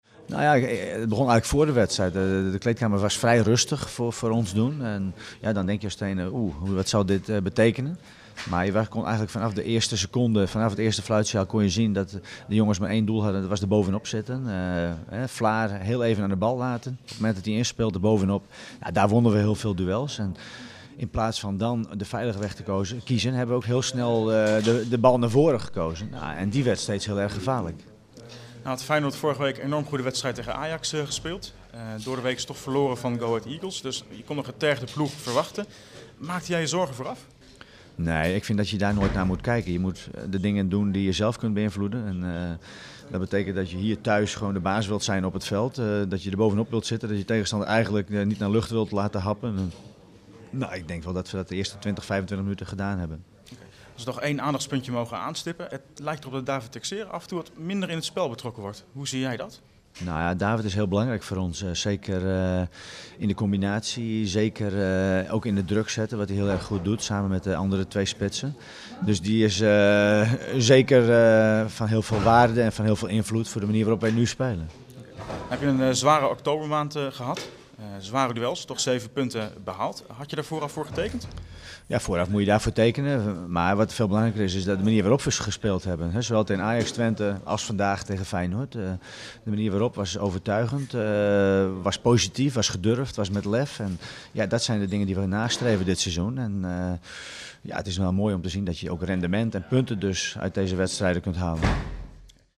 Luister hier naar de reactie van trainer Pieter Huistra